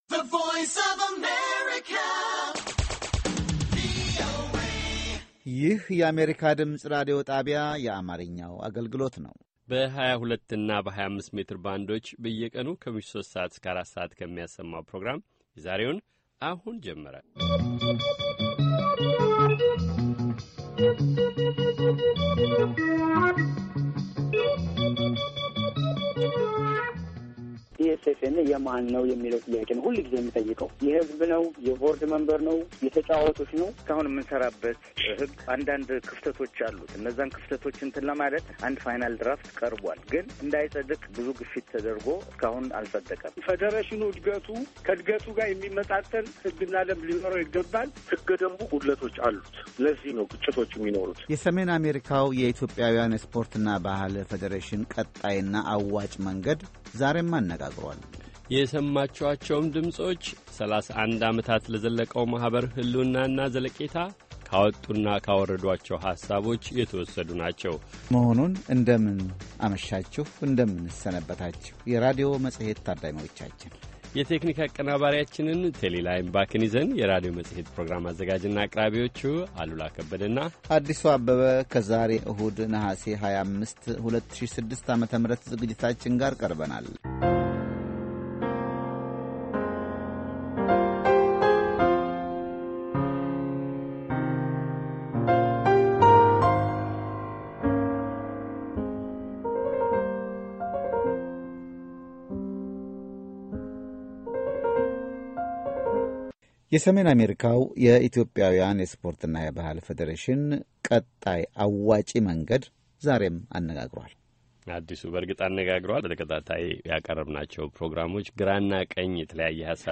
ESFNA 2nd discussion 08/31/14 - p1